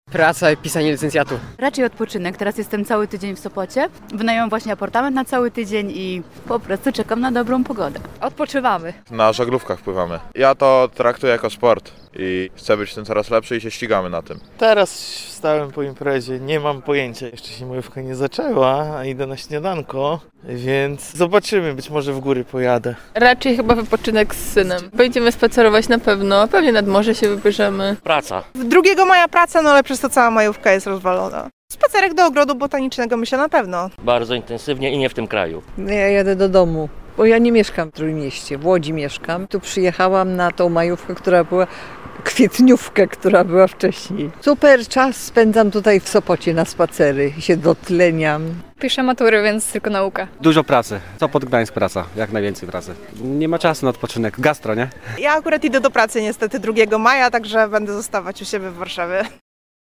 Wyjazd ze znajomymi, praca, a może przygotowania do matury – jakie plany mają mieszkańcy Trójmiasta na długi weekend majowy? O to zapytał ich nasz reporter.